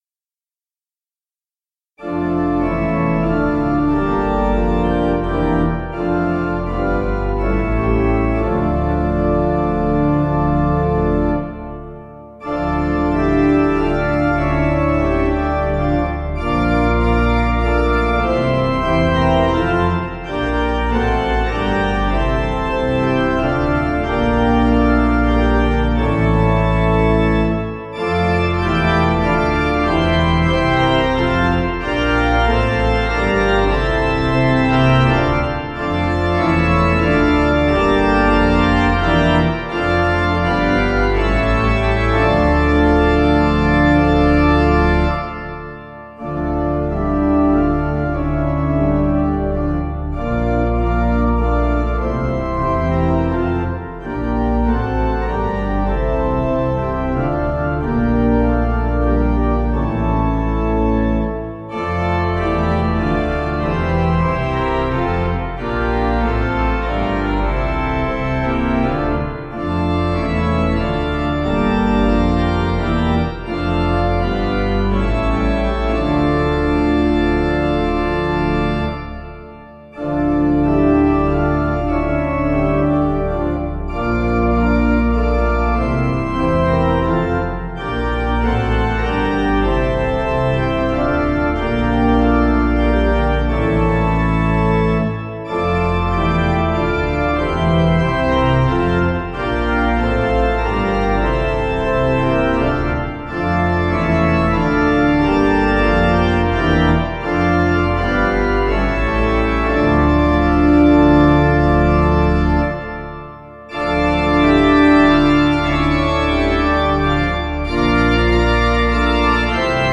Organ
(CM)   4/Dm